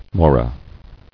[mo·ra]